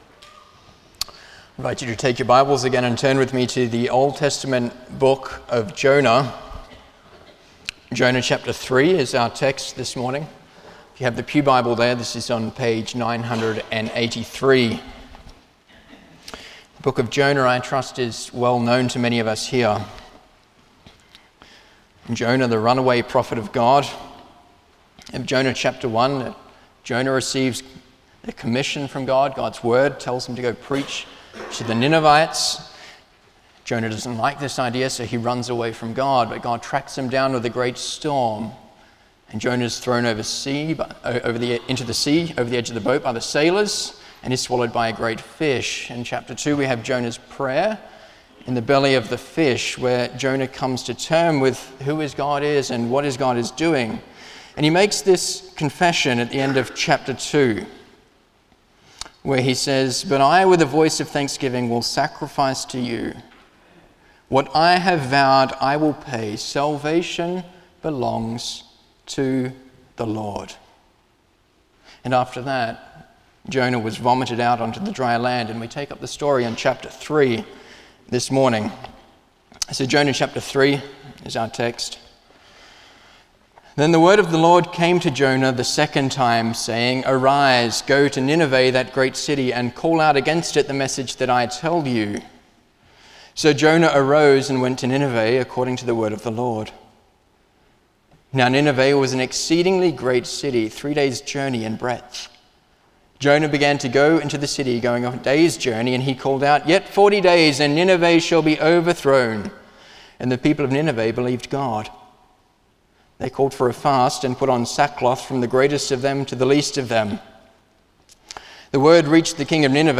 Service Type: Sunday morning
07-Sermon.mp3